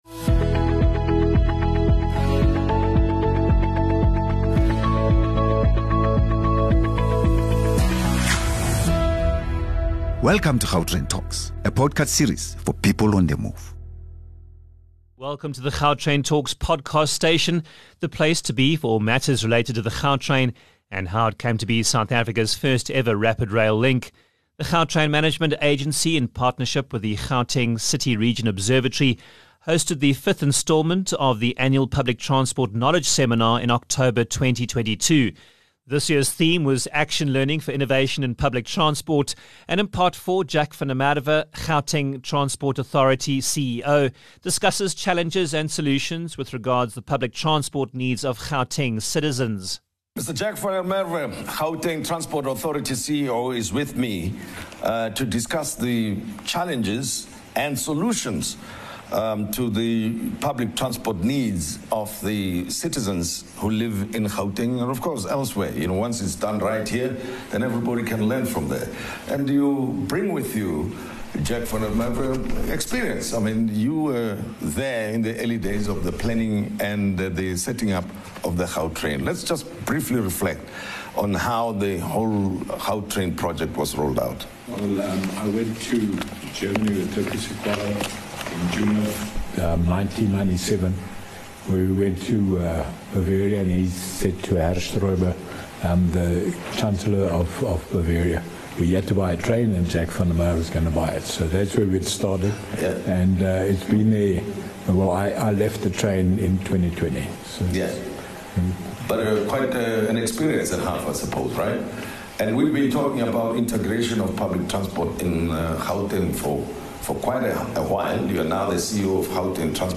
The Gautrain Management Agency in partnership with the Gauteng City Region Observatory hosted the 5th installment of the annual Public Transport Knowledge Seminar in October 2022.